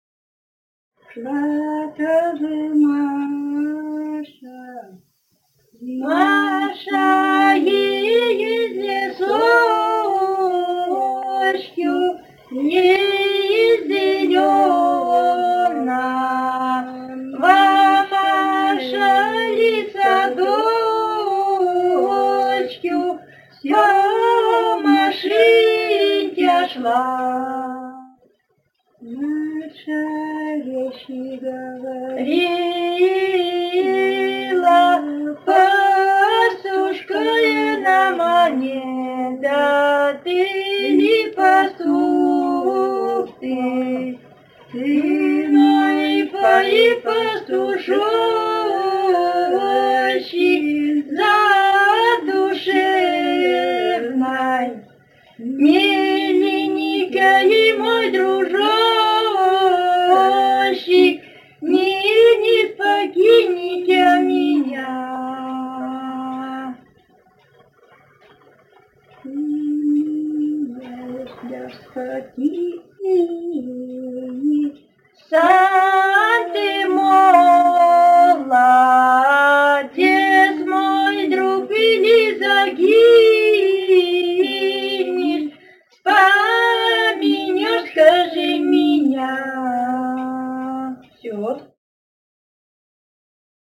с. Белое Катон-Карагайского р-на Восточно-Казахстанской обл.